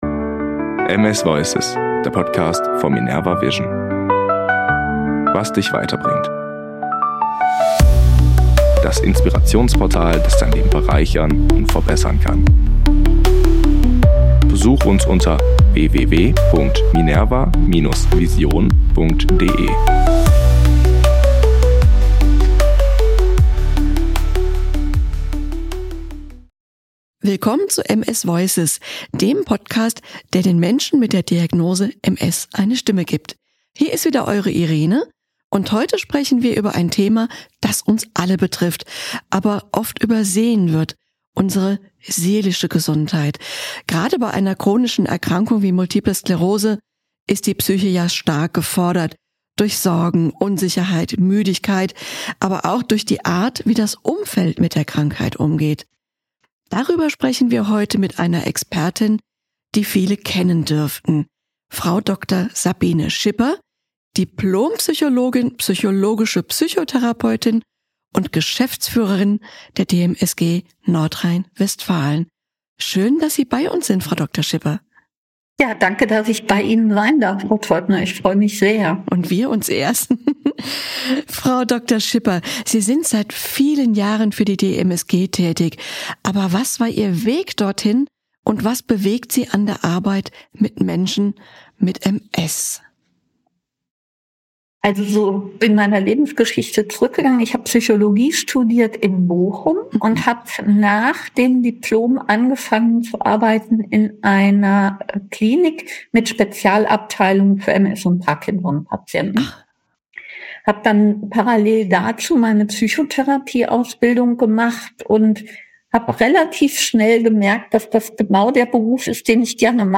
Ein Gespräch, das berührt, stärkt und Mut macht.